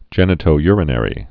(jĕnĭ-tō-yrə-nĕrē)